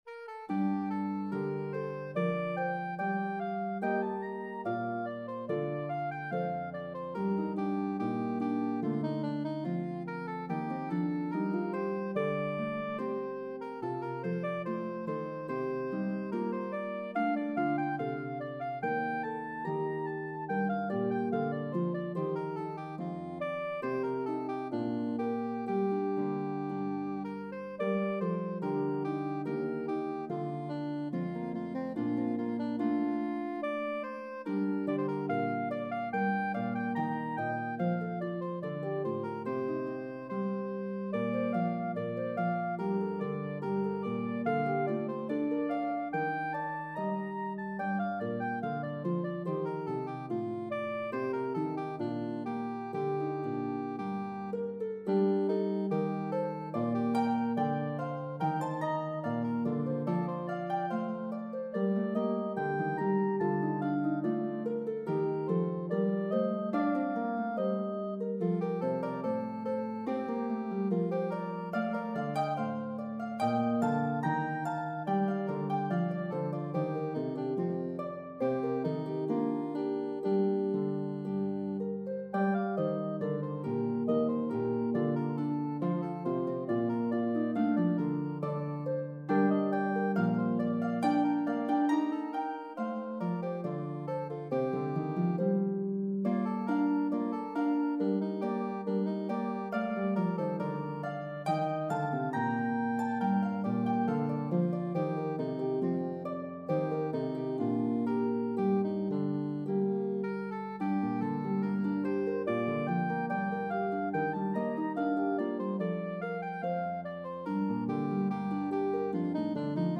This beautiful Aeolian melody has a debated history.
Italian Baroque piece